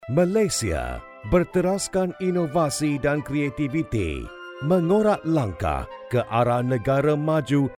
马来西亚语翻译团队成员主要由中国籍和马来籍的中马母语译员组成，可以提供证件类翻译（例如，驾照翻译、出生证翻译、房产证翻译，学位证翻译，毕业证翻译、成绩单翻译、无犯罪记录翻译、营业执照翻译、结婚证翻译、离婚证翻译、户口本翻译、奖状翻译等）、公证书翻译、病历翻译、马来语视频翻译（听译）、马来语语音文件翻译（听译）、技术文件翻译、工程文件翻译、合同翻译、审计报告翻译等；马来西亚语配音团队由马来西亚籍的马来语母语配音员组成，可以提供马来语专题配音、马来语广告配音、马来语教材配音、马来语电子读物配音、马来语产品资料配音、马来语宣传片配音、马来语彩铃配音等。
马来西亚语样音试听下载